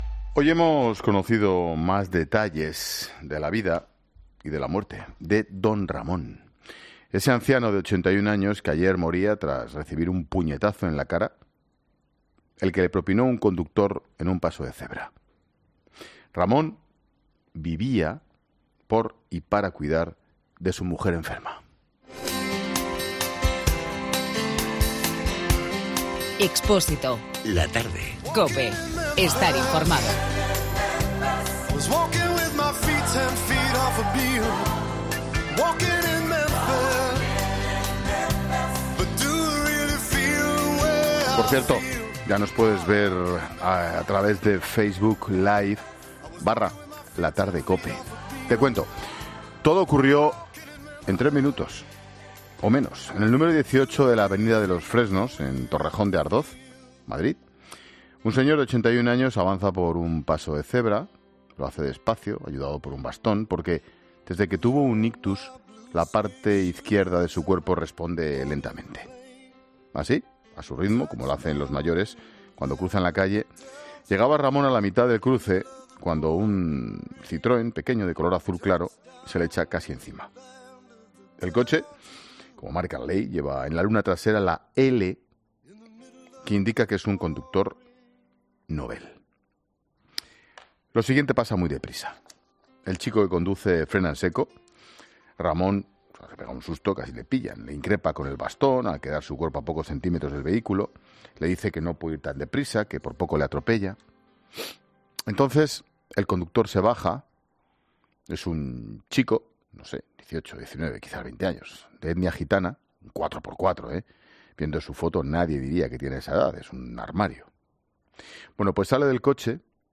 experto en Psicología Forense